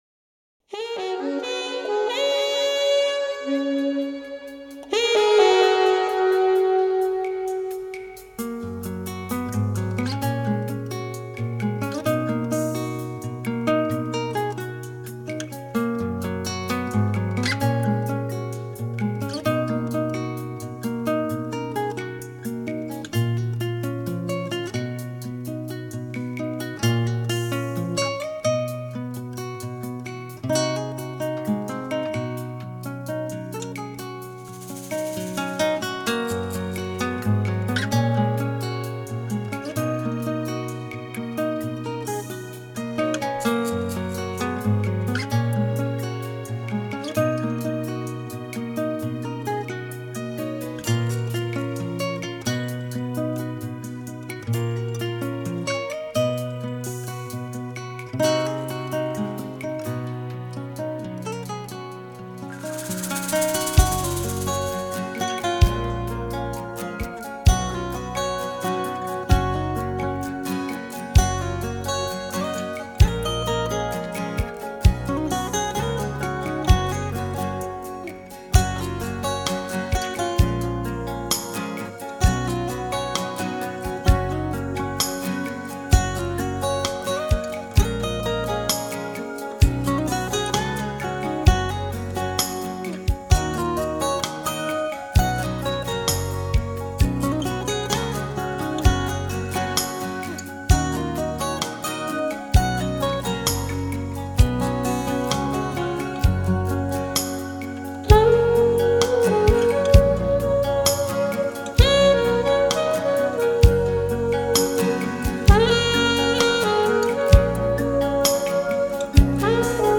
Chitarra Classica